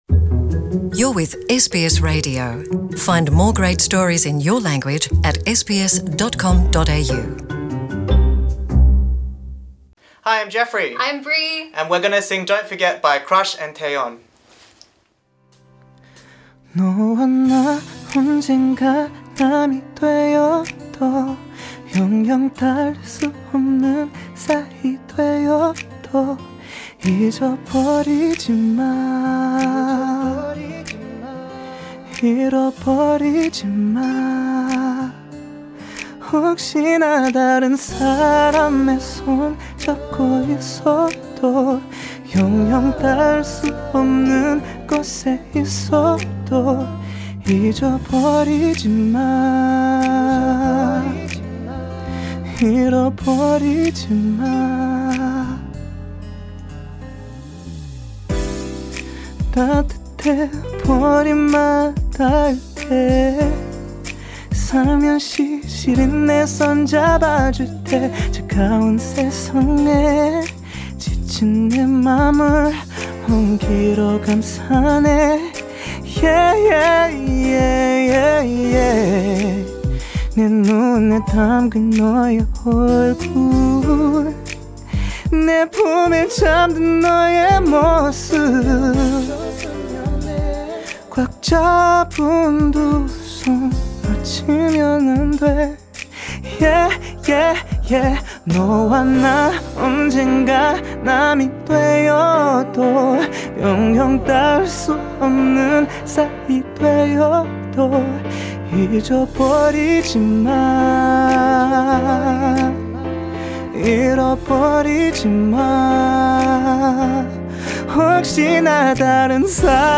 at the SBS Studio